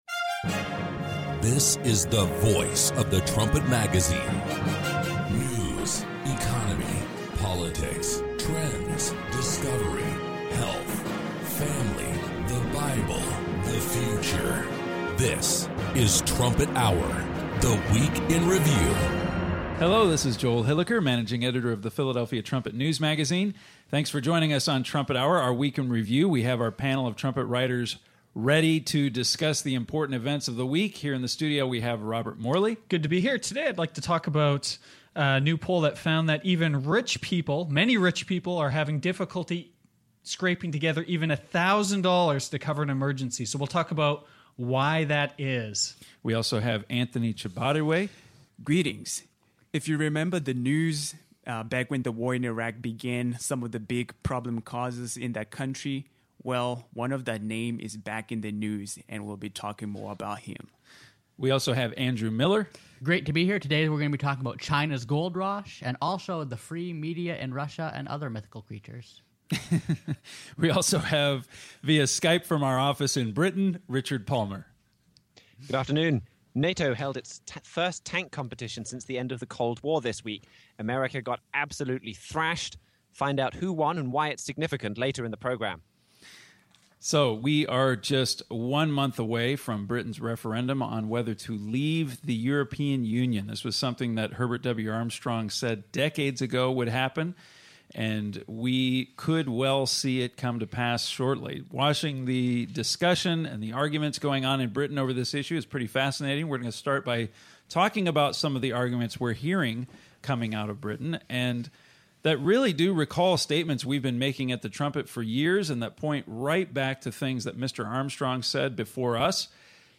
Join the discussion as Trumpet staff members compare recent news to Bible prophecy.